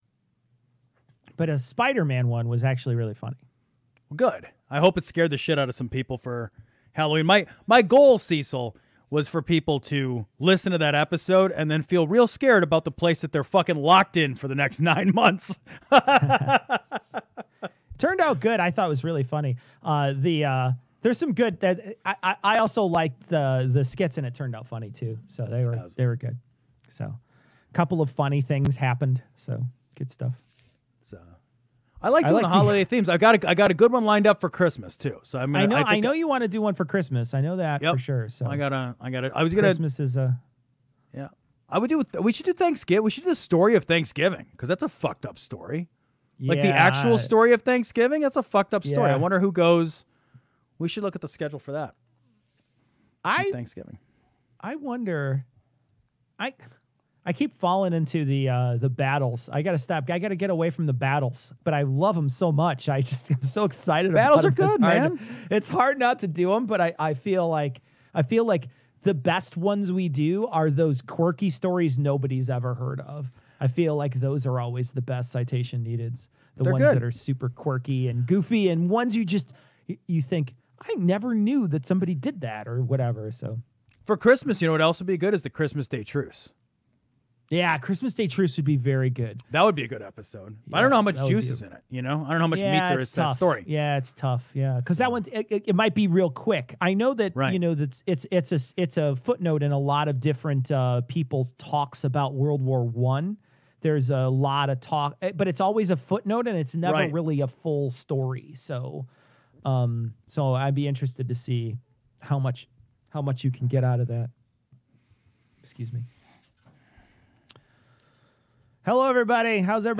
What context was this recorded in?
Tags: Livestream